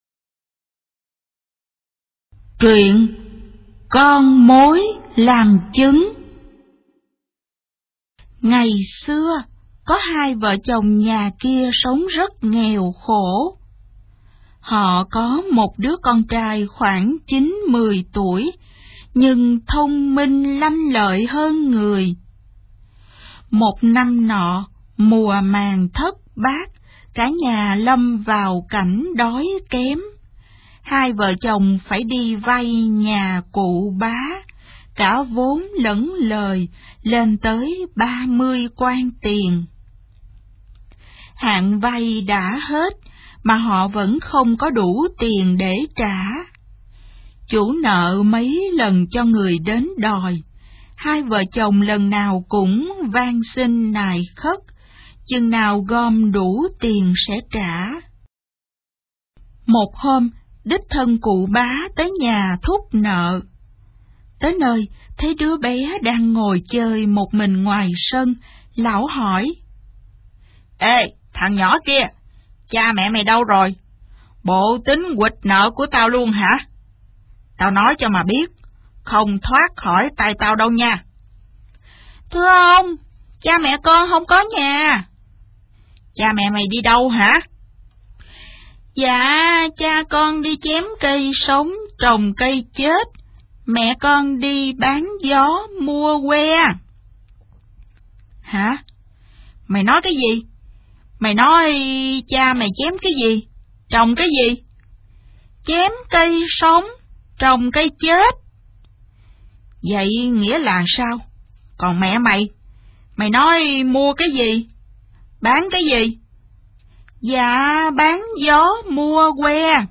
Sách nói | Chum Vàng, Chum Rắn